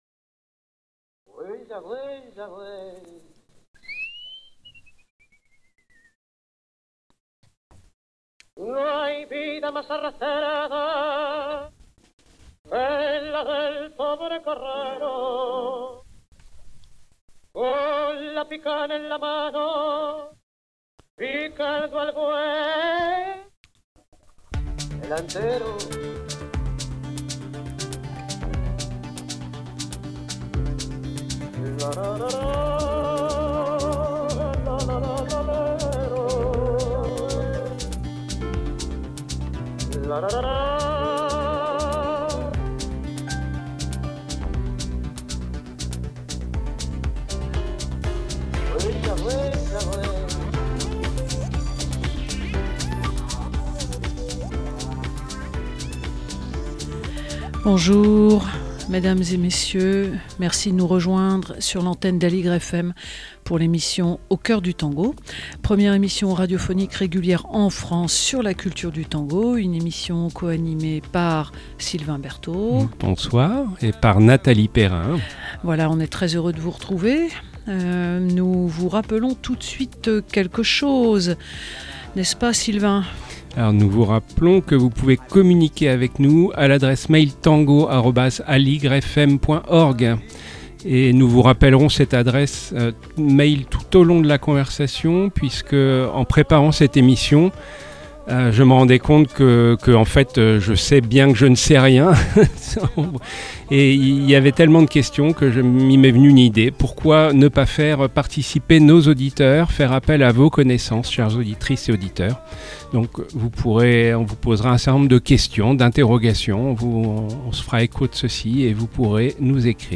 Catégorie : Musique - Le tango voyageurDiffusion : 12 février 2026 (6)